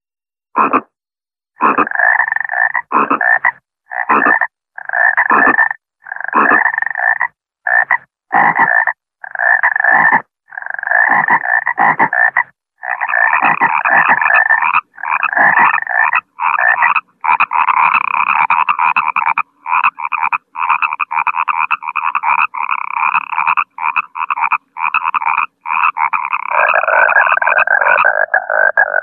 Froschquaken klingelton kostenlos
Kategorien: Tierstimmen
froschquaken.mp3